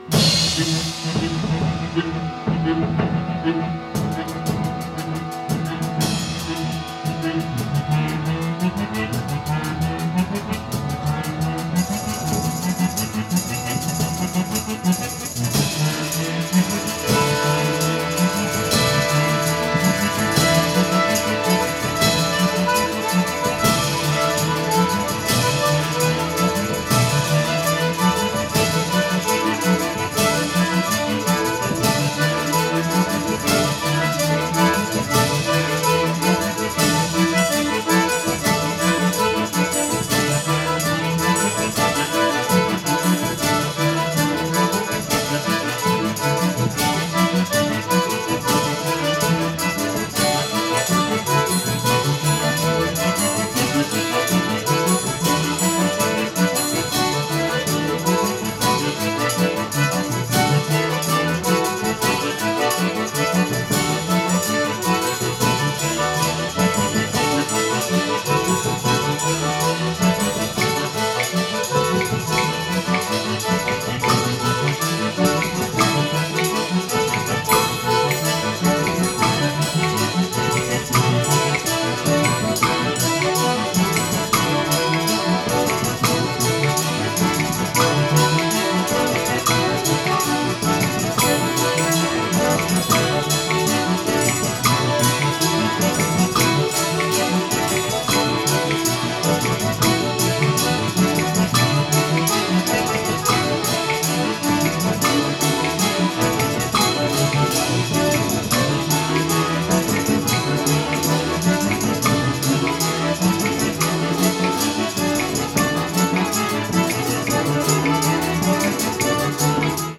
持続音を活かしたアコーディオンによる演奏と、バトゥカーダやクレズマーのリズムが交錯！
持続音を活かしたアコーディオンによる演奏から、バトゥカーダやクレズマーのリズムを交錯させていく展開が見事な楽曲！